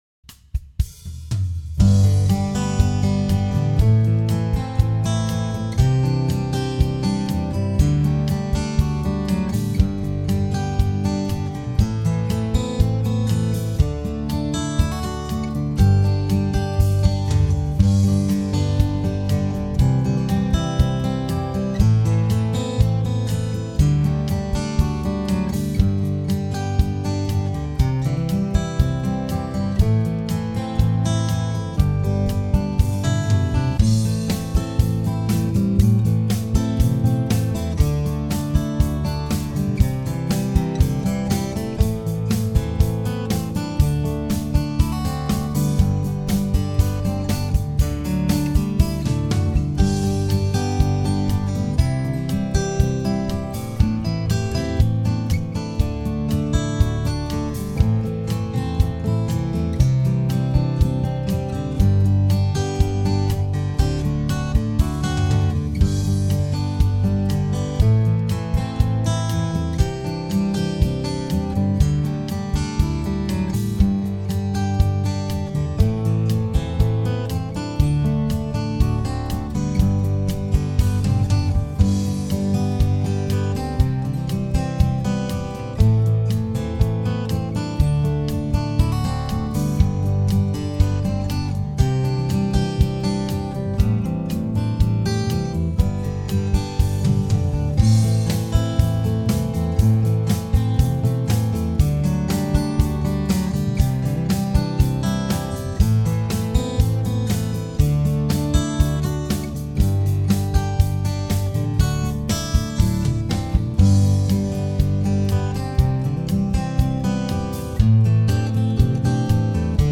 Home > Music > Pop > Bright > Smooth > Happy